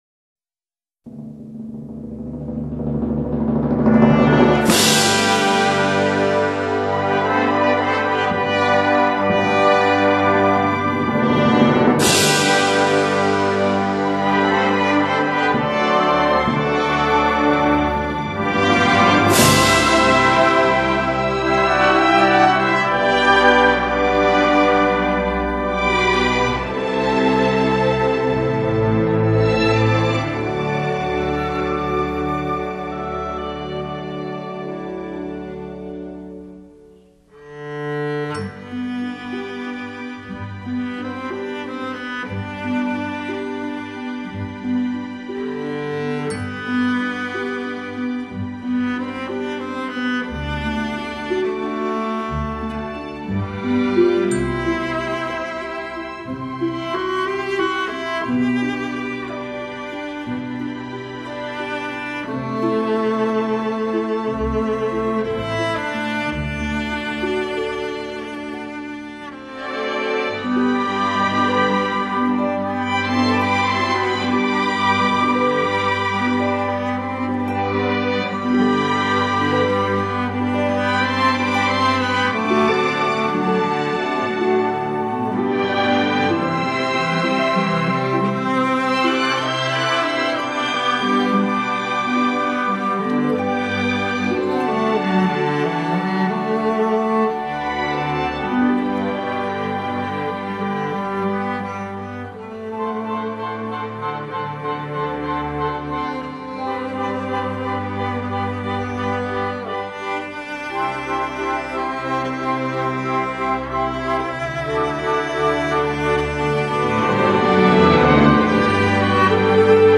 联袂打造大提琴独奏典范